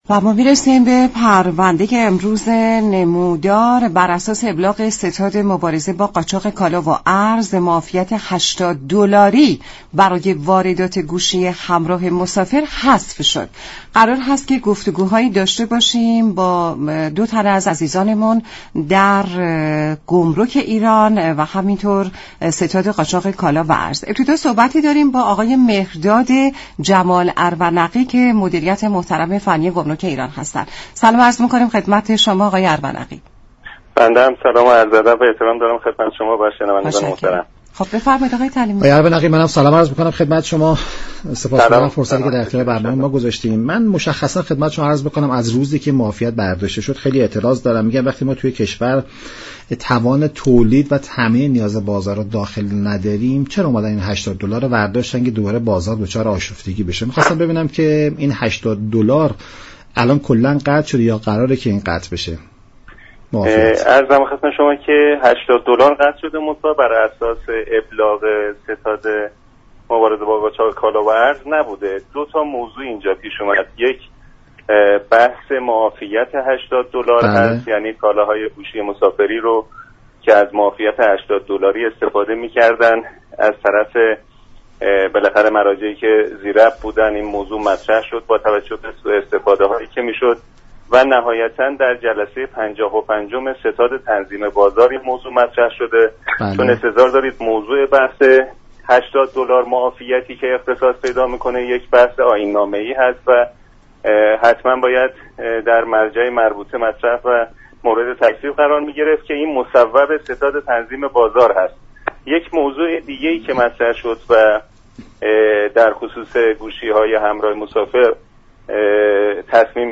معاون فنی و امور گمركی گمرك ایران در گفت و گو با رادیو ایران گفت: بر اساس ابلاغ ستاد مبارزه با قاچاق كالا به گمرك كل كشور، حقوق ورودی تلفن همراه مسافری بر اساس نرخ ارز بازار ثانویه (سامانه سَنا) محاسبه می شود.